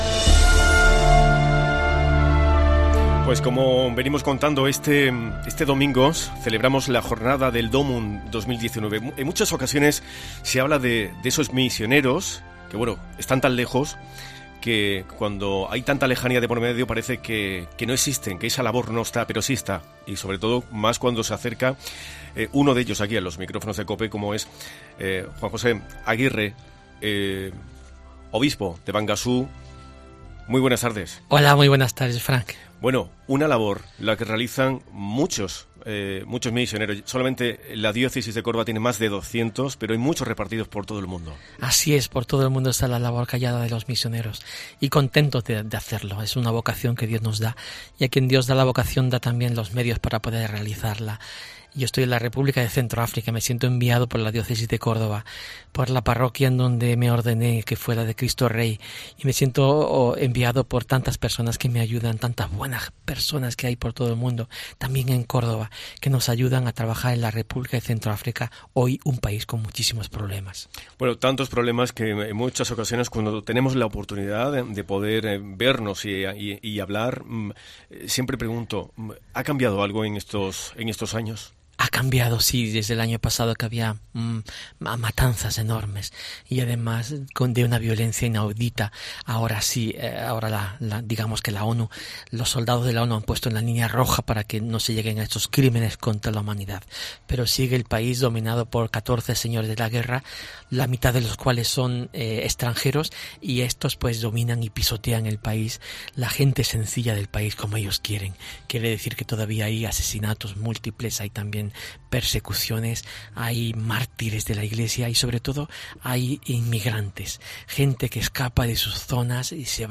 AUDIO: El obispo de Bangassou ha hablado de la labor de los misioneros con motivo de la celebración de la Jornada Mundial de las Misiones